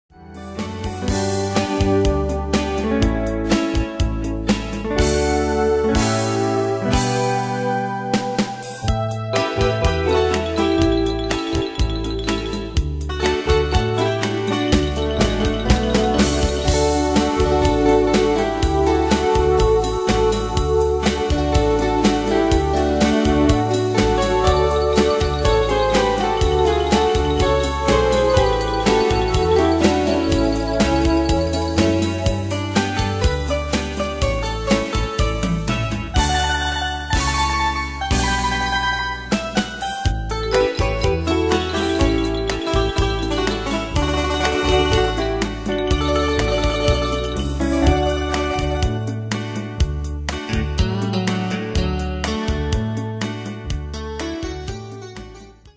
Style: Country Année/Year: 1999 Tempo: 123 Durée/Time: 4.22
Danse/Dance: ChaCha Cat Id.
Pro Backing Tracks